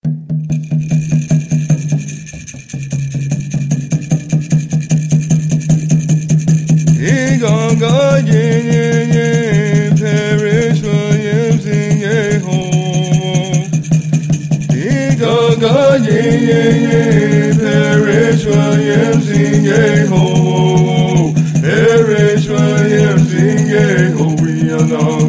8.Ponca Song